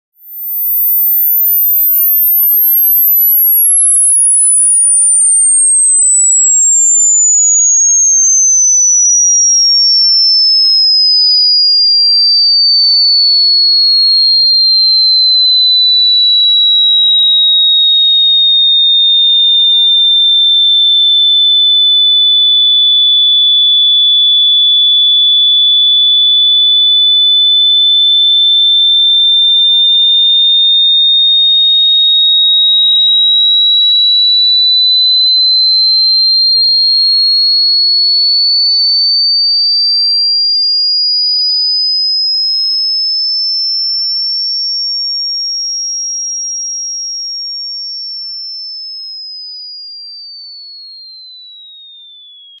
Ультразвук для отпугивания комаров